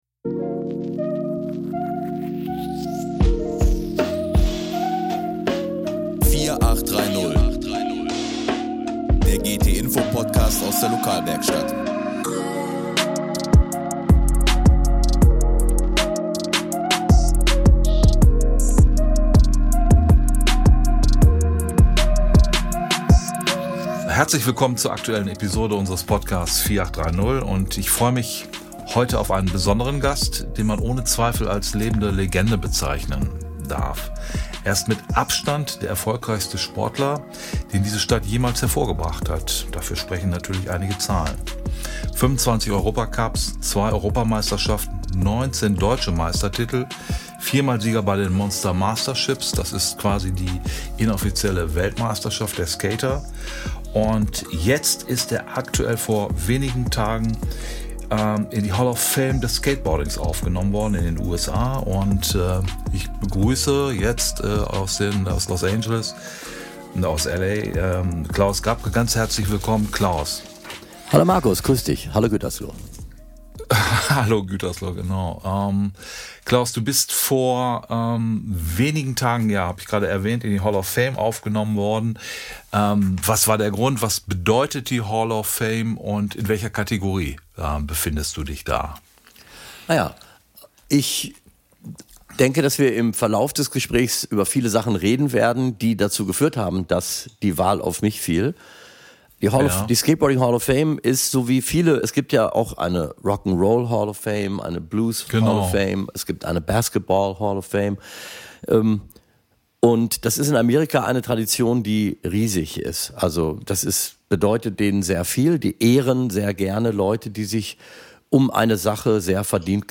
spircht mit der Skate-Ikone remote in den USA.